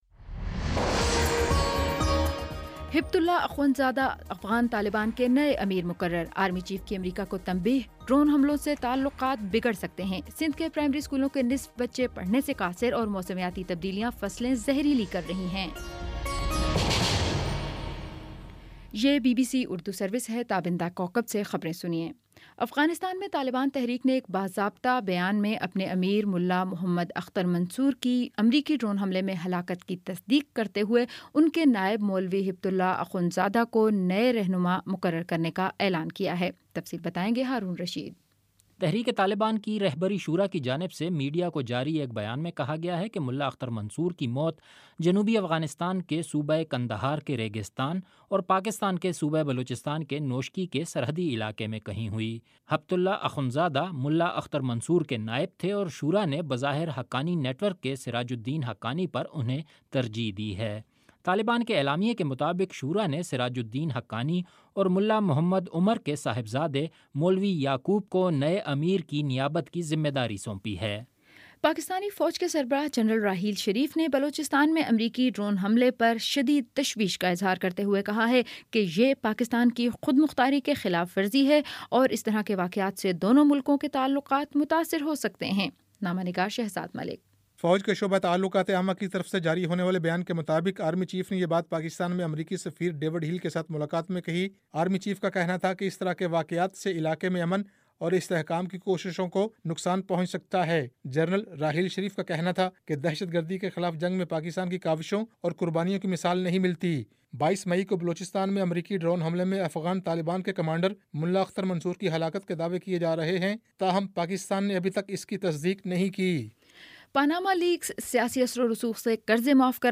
مئی 25 : شام چھ بجے کا نیوز بُلیٹن